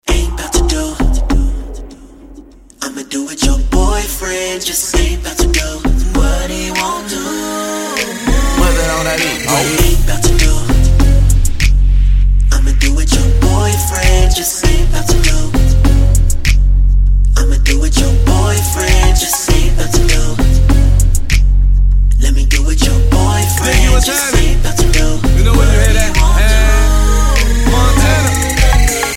• Качество: 320, Stereo
Hip-Hop & RNB